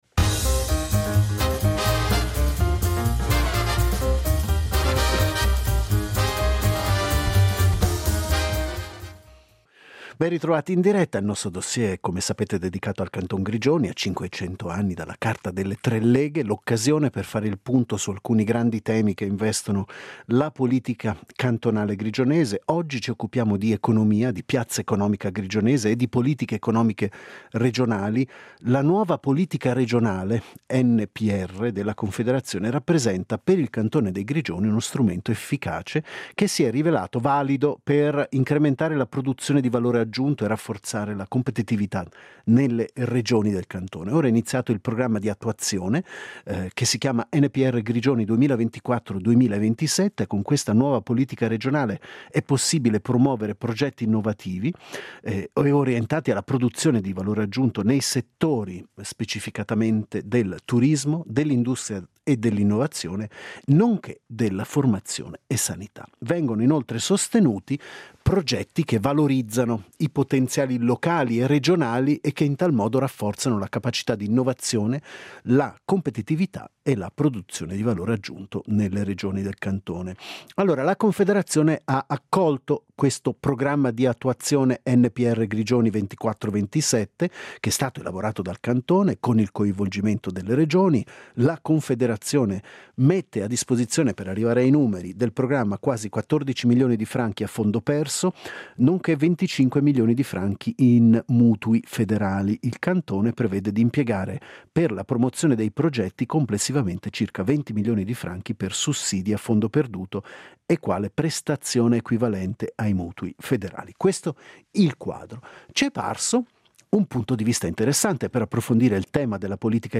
Quarto segmento del Dossier dedicato ai Grigioni, ci siamo occupati di politiche economiche regionali. È da poso stato approvato il piano attuativo della Nuova Politica regionale (NPR). Ne abbiamo parlato con l’economista